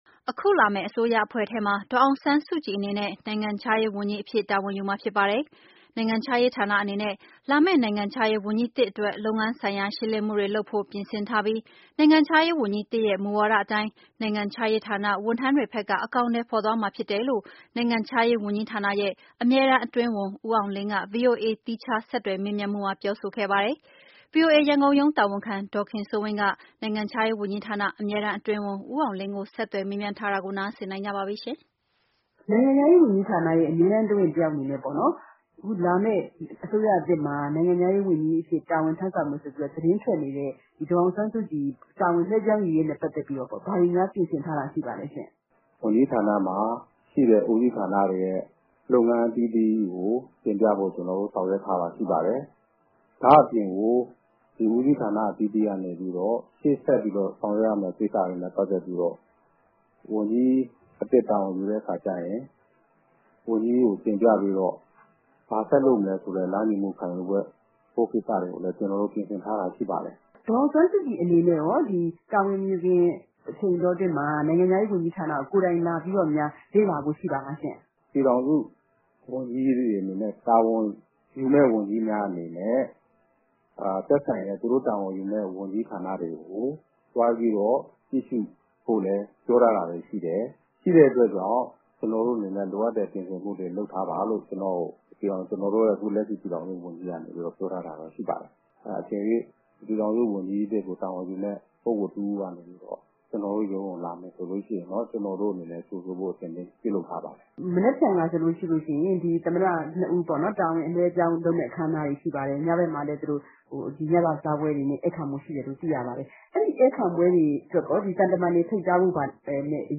နိုင်ငံခြားရေး အမြဲတန်းအတွင်းဝန် ဦအောင်လင်းနှင့်မေးမြန်းခြင်း